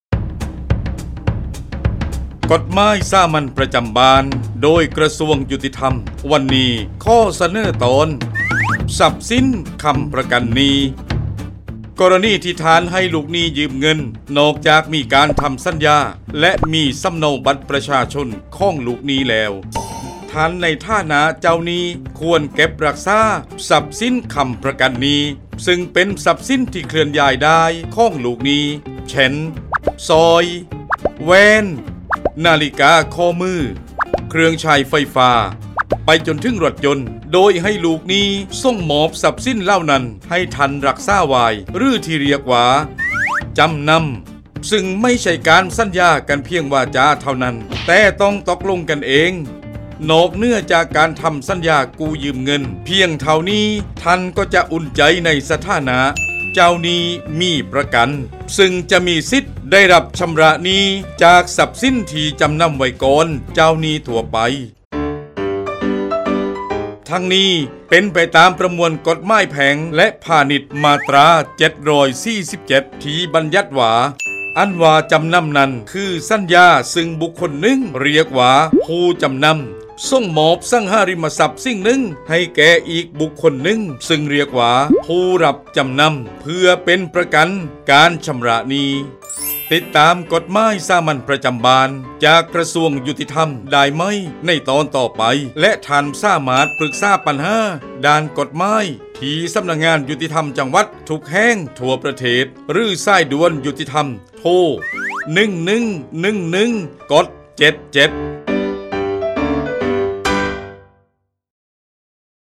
กฎหมายสามัญประจำบ้าน ฉบับภาษาท้องถิ่น ภาคใต้ ตอนทรัพย์สินค้ำประกันหนี้
ลักษณะของสื่อ :   บรรยาย, คลิปเสียง